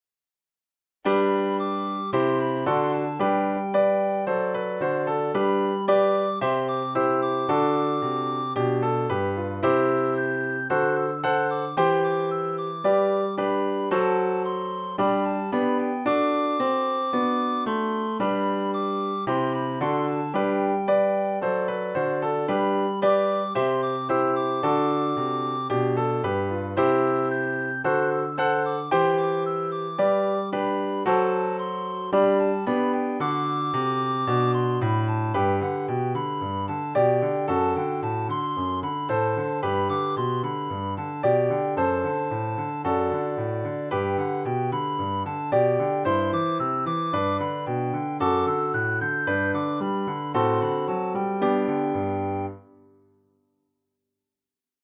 für Sopranblockflöte (Violine, Flöte) und Klavier